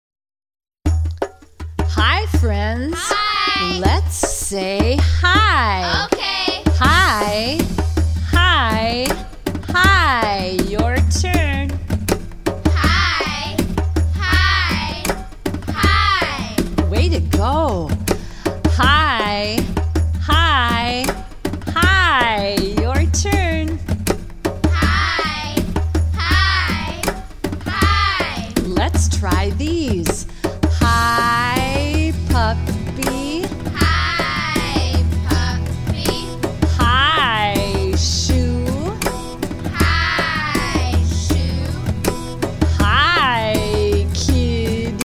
slow version